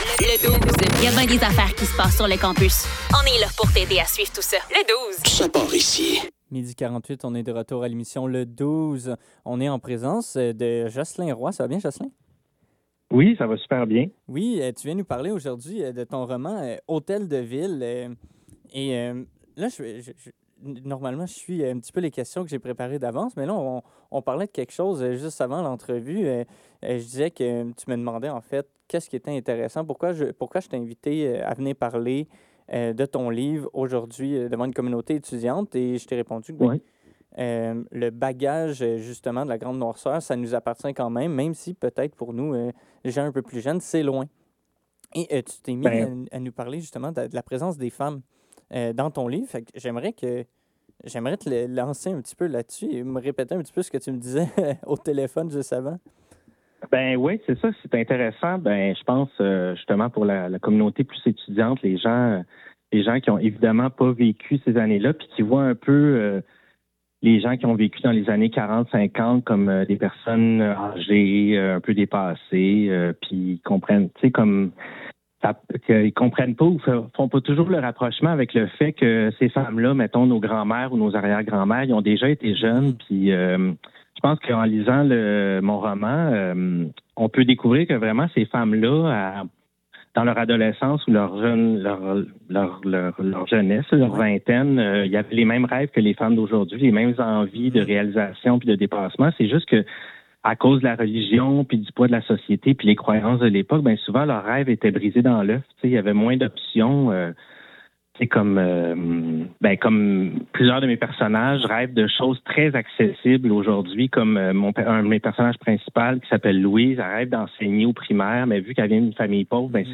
Le Douze - Entrevue